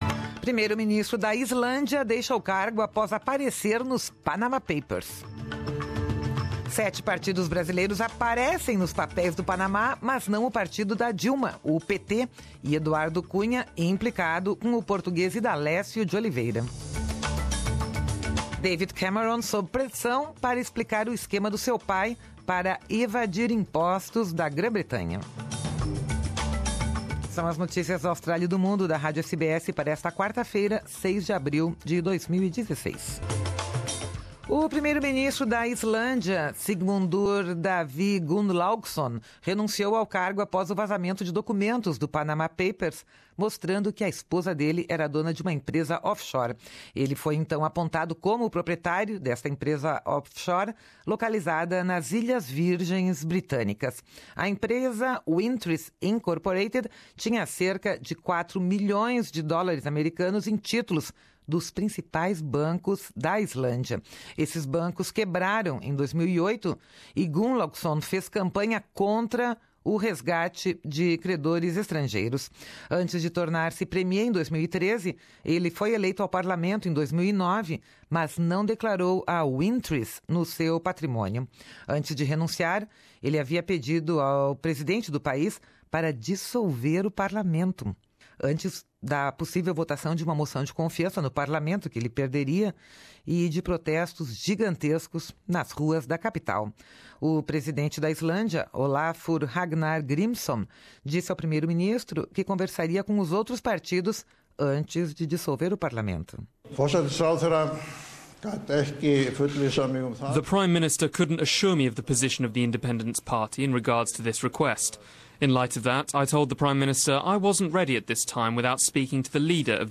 Falas de Obama, Ramos Fonseca, Jeremy Corbyn e Grimsson sobre Panama Papers